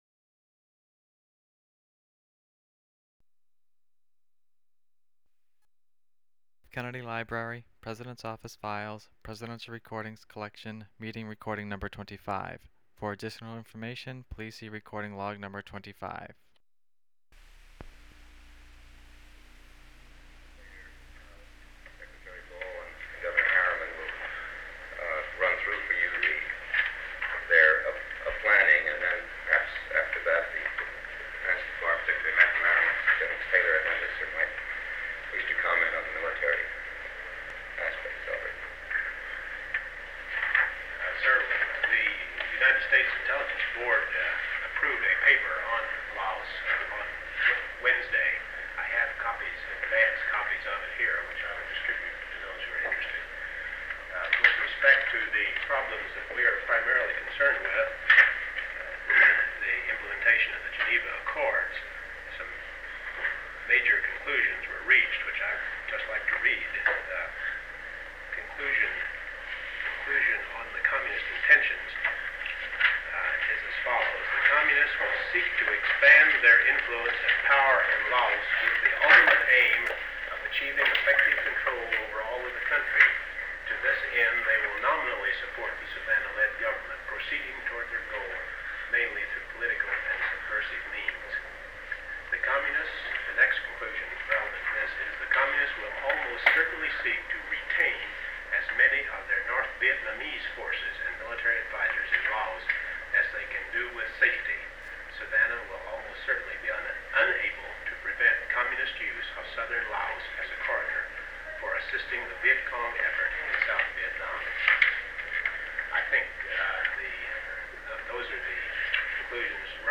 Secret White House Tapes | John F. Kennedy Presidency Meeting on Laos Rewind 10 seconds Play/Pause Fast-forward 10 seconds 0:00 Download audio Previous Meetings: Tape 121/A57.